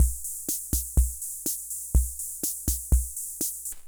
Loop11.wav